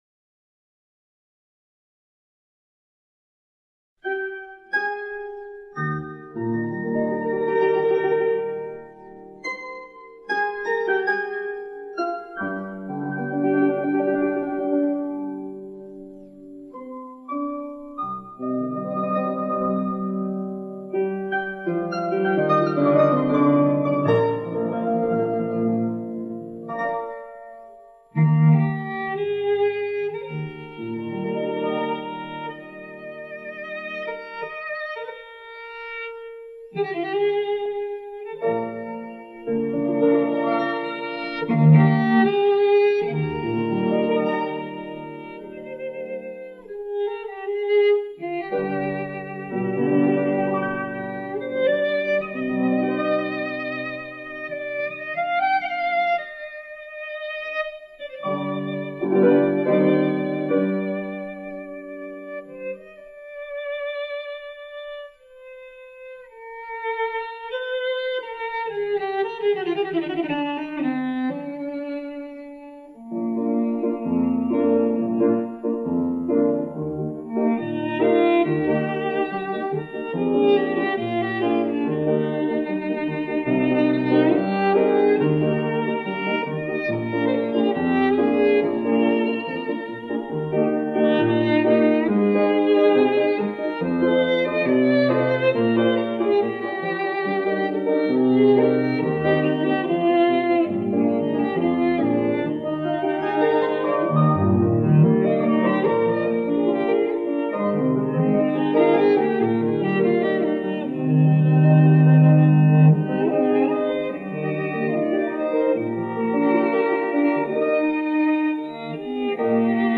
大提琴曲